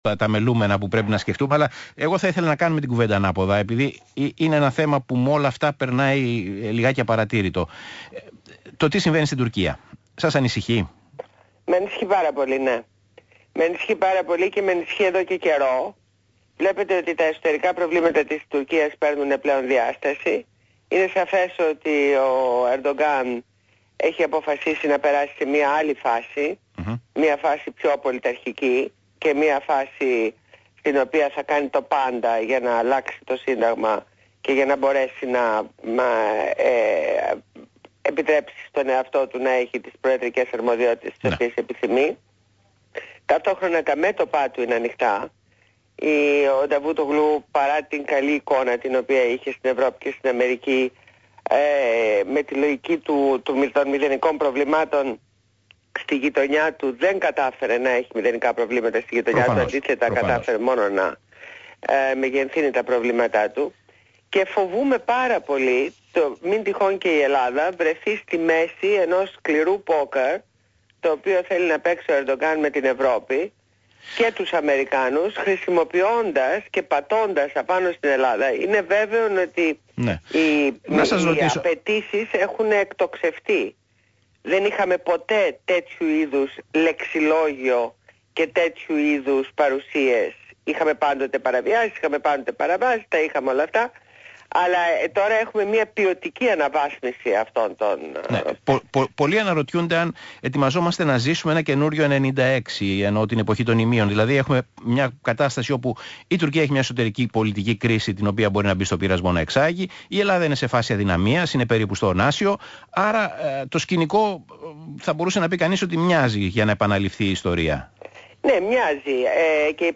Συνέντευξη στο ραδιόφωνο του ΣΚΑΙ στο δημοσιογράφο Π. Τσίμα.